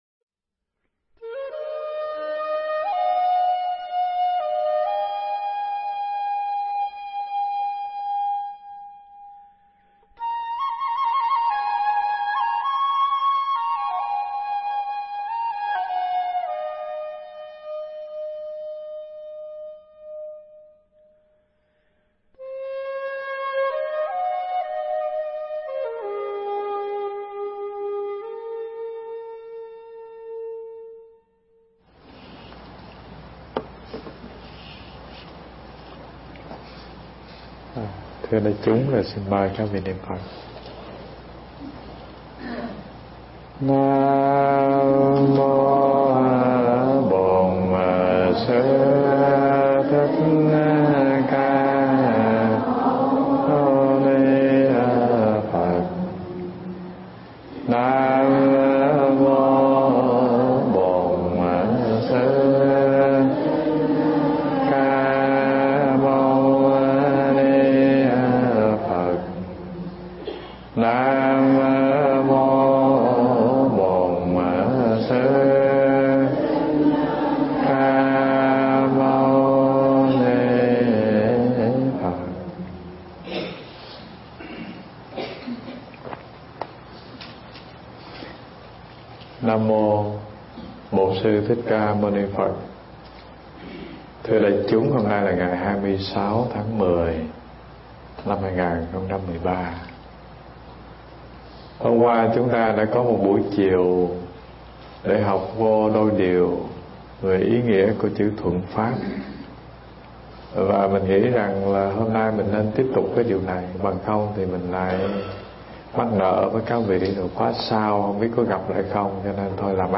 Nghe Mp3 thuyết pháp Tùy Thuận Pháp Phần 2